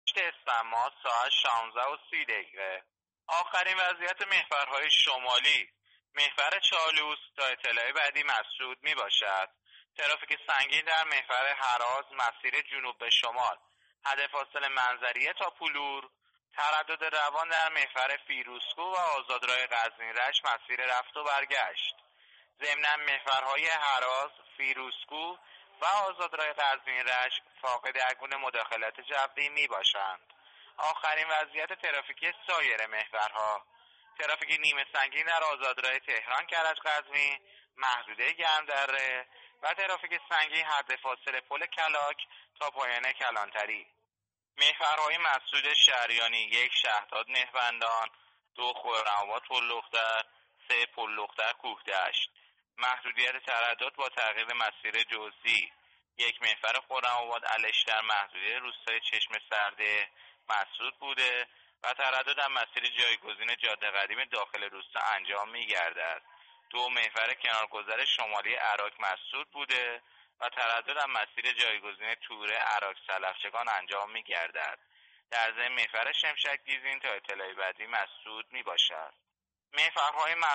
گزارش رادیو اینترنتی از آخرین وضعیت ترافیکی جاده‌ها تا ساعت ۱۶:۳۰پنجشنبه ۸ اسفند ۱۳۹۸